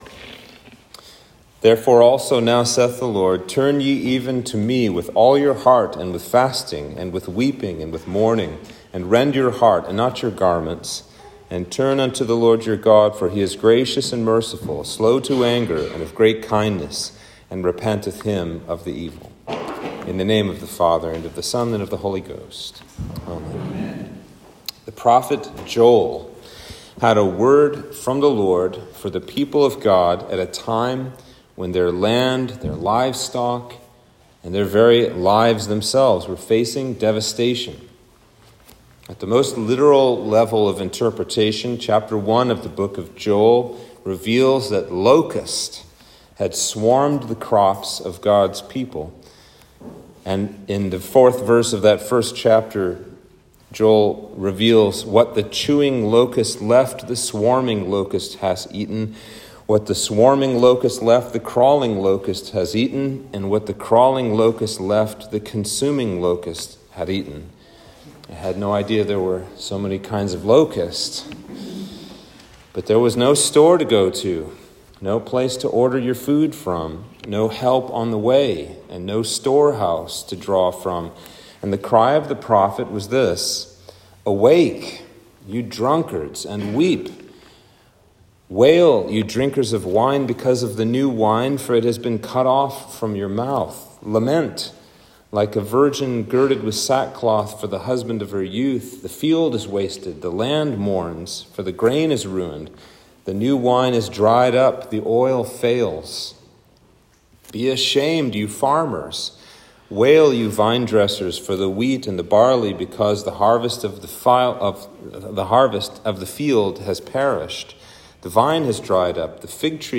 Sermon for Ash Wednesday